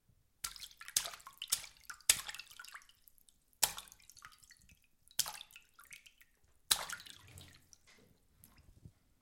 飞溅的水
描述：水轻轻地溅在水槽里。
Tag: 水槽 飞溅 液体